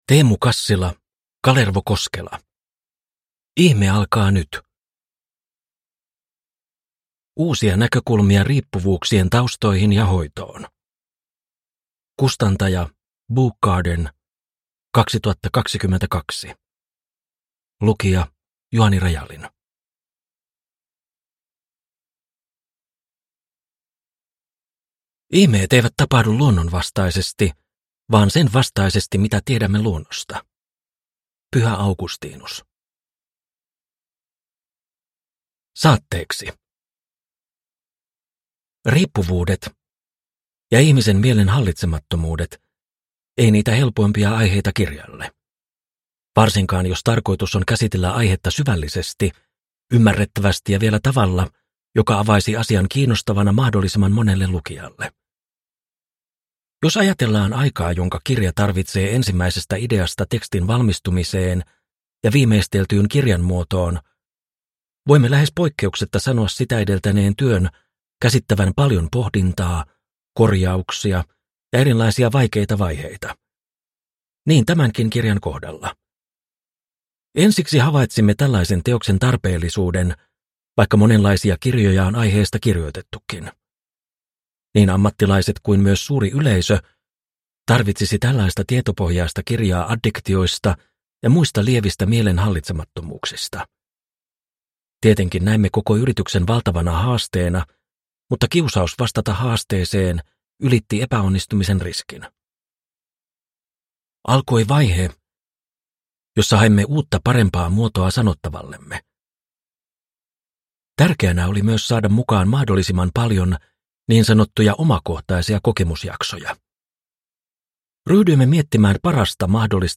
Ihme alkaa nyt – Ljudbok – Laddas ner